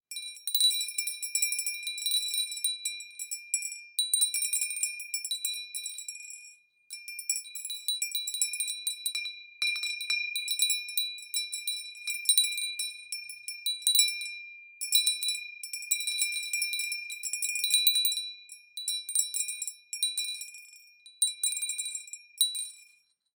Two Glass Bells
beautiful bell bells bell-set bell-tone chime clanging cow sound effect free sound royalty free Animals